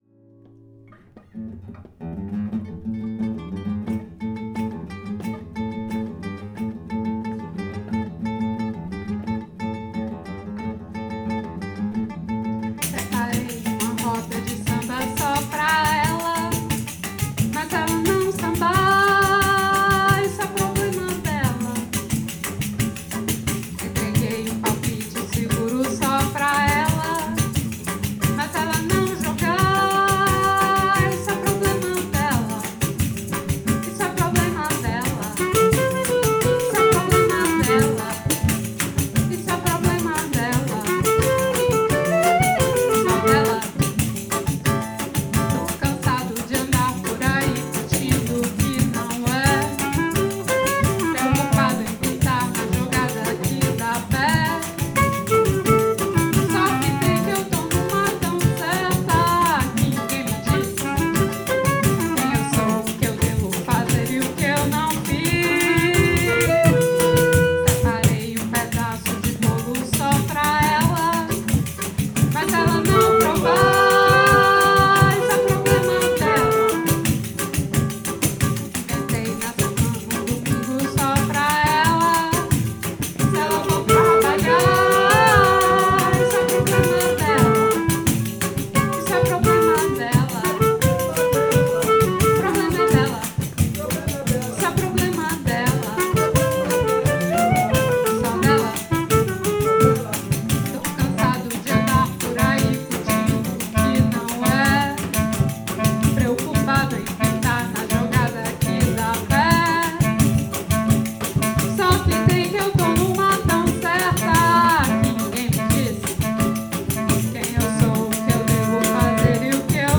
Rec atelier